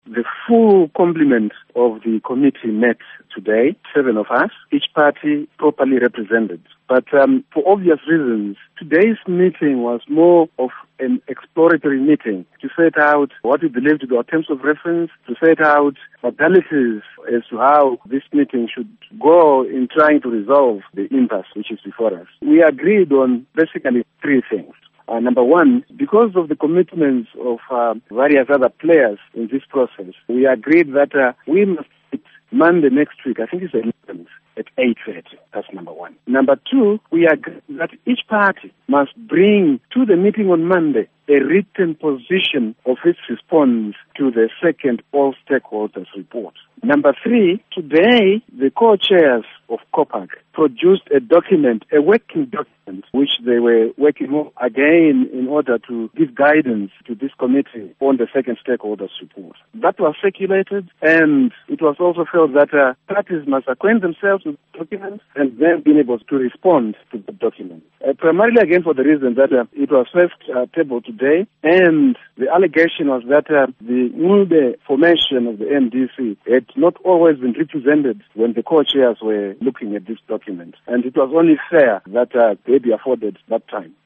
Interview With Minister Eric Matinenga